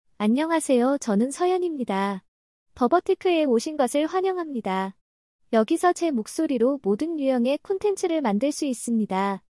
Seoyeon — Female Korean AI Voice | TTS, Voice Cloning & Video | Verbatik AI
Seoyeon is a female AI voice for Korean.
Voice sample
Listen to Seoyeon's female Korean voice.
Seoyeon delivers clear pronunciation with authentic Korean intonation, making your content sound professionally produced.